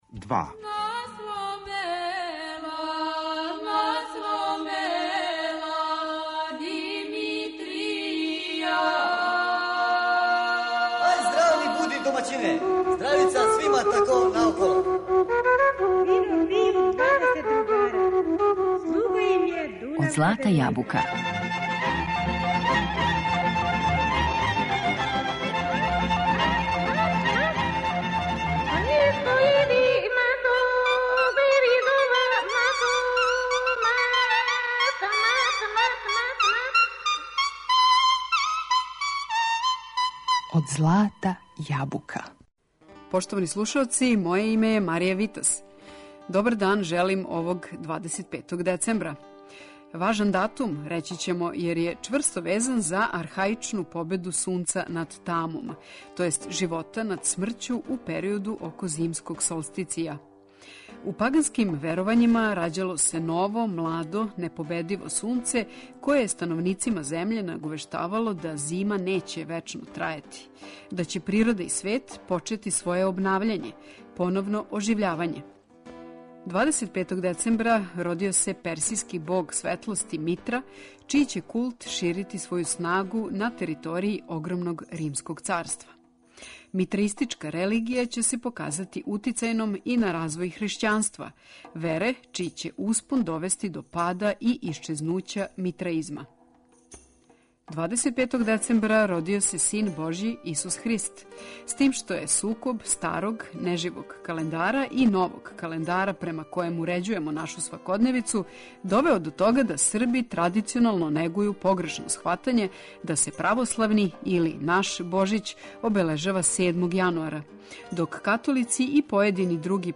хрватске народне песме